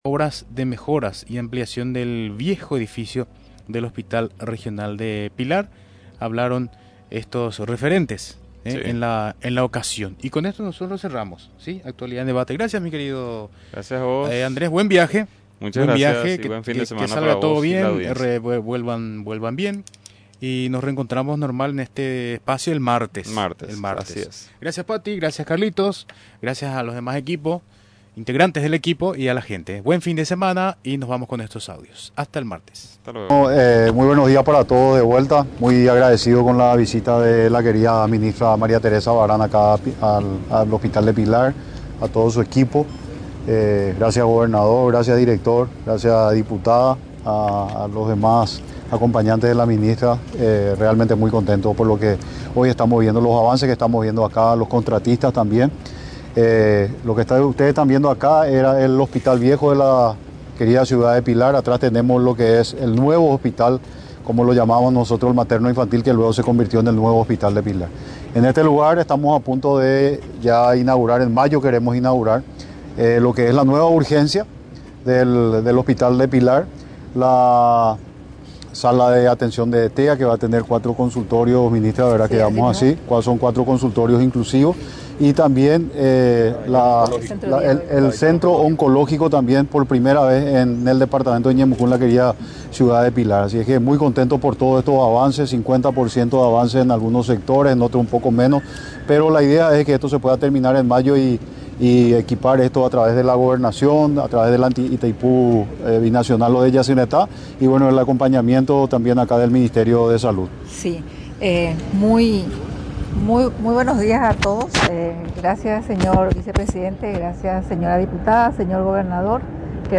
El Gobierno Nacional a través del Ministerio de Urbanismo, Vivienda y Hábitat, entregó viviendas a familias del departamento de Ñeembucú, en un acto que contó con la presencia del vicepresidente de la República, Pedro Alliana, quién recordó que el compromiso y la prioridad fue entregar viviendas propias a cada uno de los paraguayos.